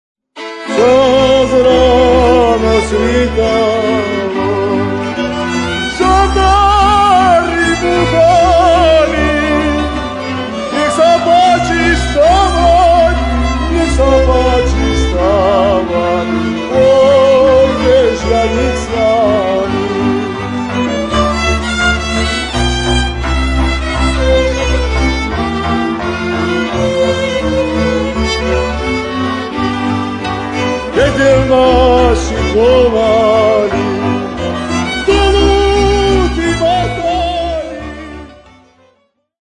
Kategória: Ľudová hudba